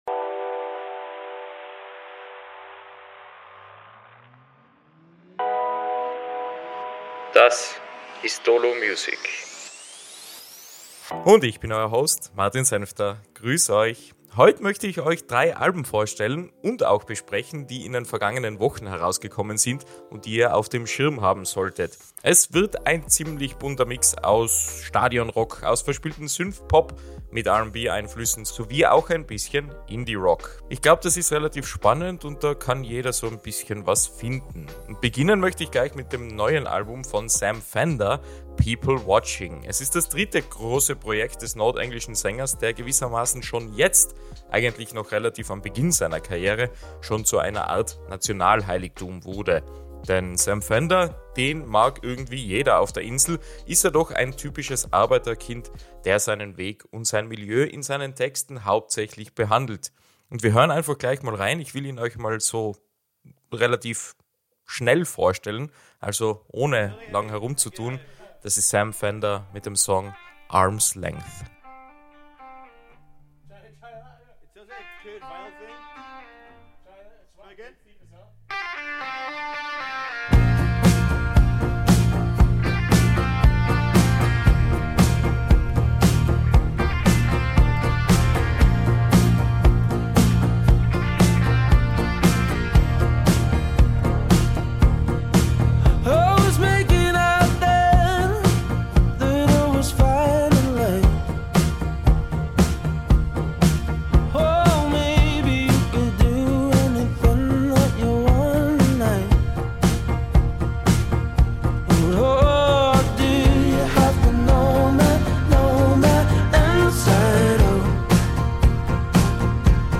Beschreibung vor 1 Jahr In der neuesten Episode von Dolo Music gehen wir auf eine musikalische Reise durch drei herausragende Alben, die den Februar 2025 prägten. Vom Stadion-Rock über Synth-Pop mit R&B-Einflüssen bis hin zu Indie-Rock – diese Episode bietet Unterhaltung!
Wir sprechen über die Themen von Vergänglichkeit, Sucht und Selbstfindung und hören in zwei der stärksten Tracks des Albums rein.